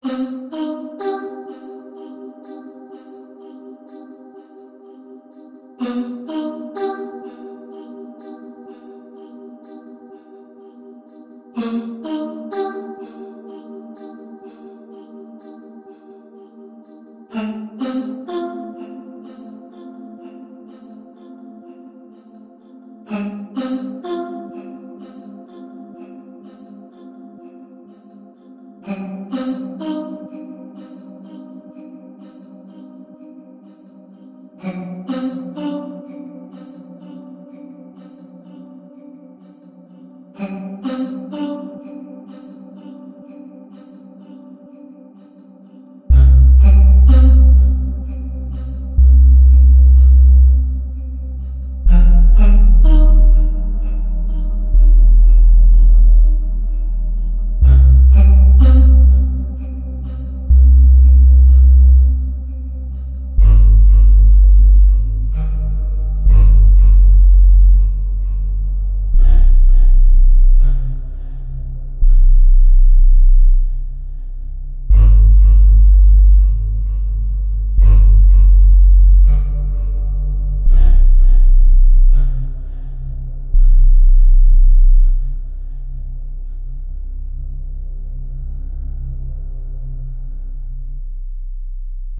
• Music is loop-able, but also has an ending